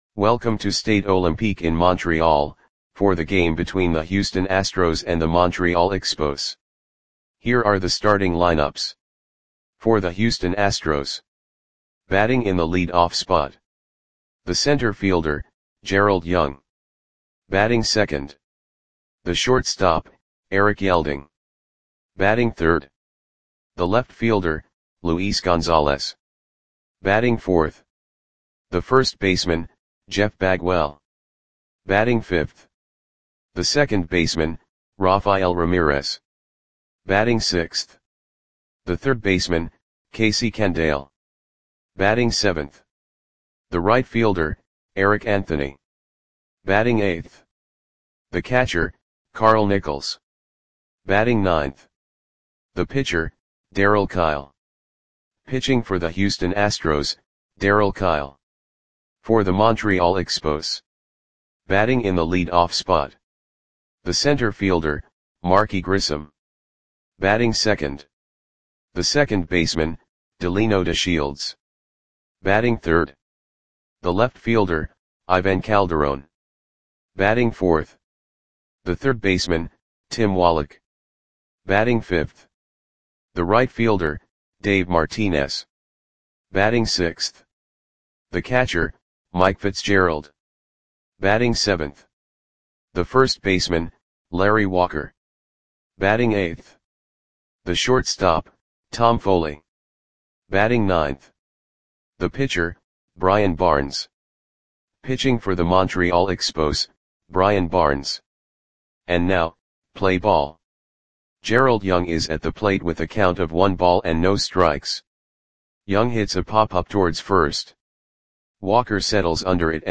Lineups for the Montreal Expos versus Houston Astros baseball game on June 18, 1991 at Stade Olympique (Montreal, QUE).
Click the button below to listen to the audio play-by-play.